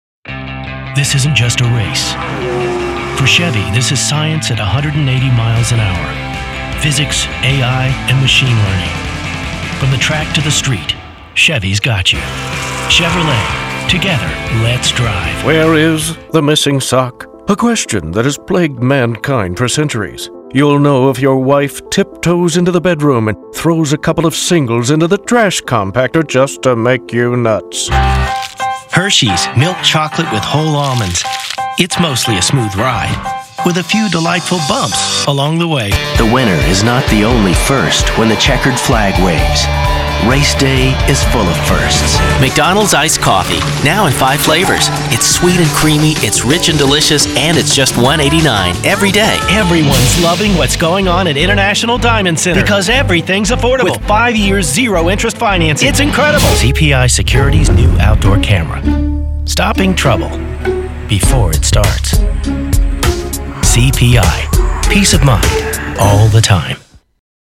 Voiceover Male